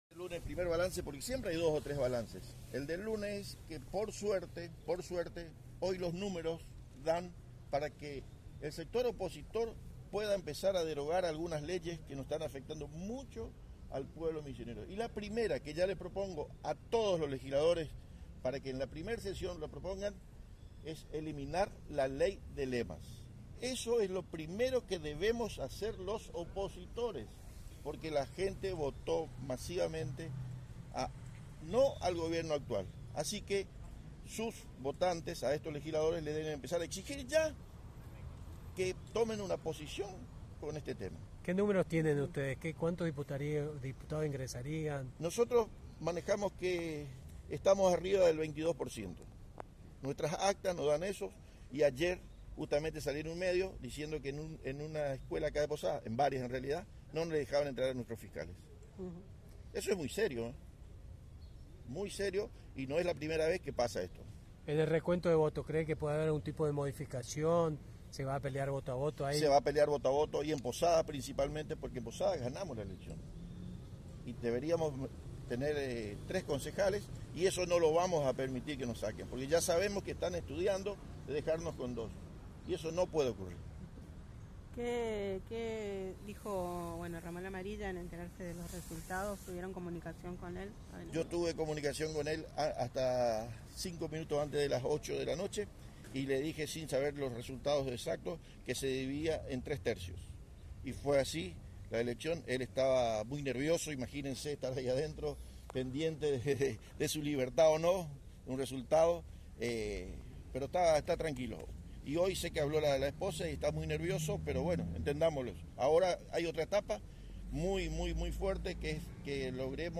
El diputado Miguel Núñez (Frente Unidos por el Futuro) indicó ayer, en conferencia de prensa que, sus datos superan el 22 % para diputados y en concejales podrían acceder a tres bancas y no dos, como se publicó. Propondrán la eliminación de la Ley de Lemas, en la primera sesión.